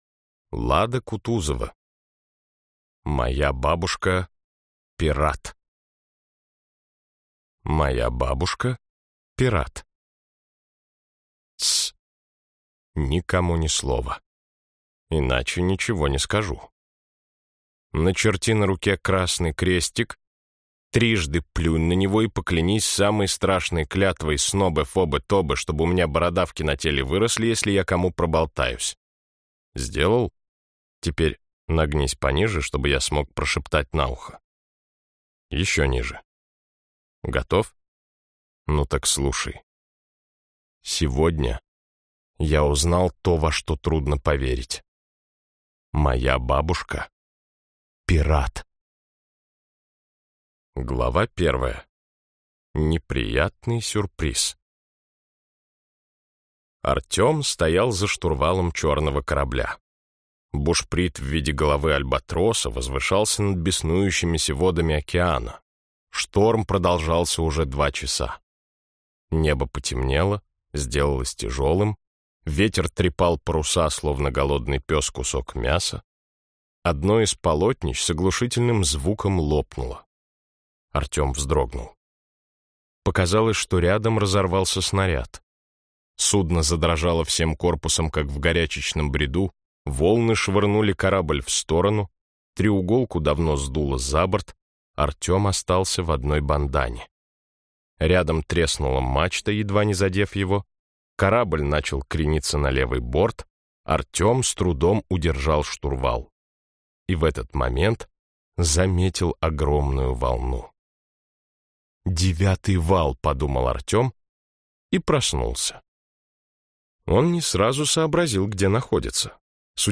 Аудиокнига Моя бабушка – пират!